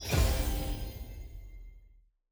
sfx-loot-button-purchase-chest-click.ogg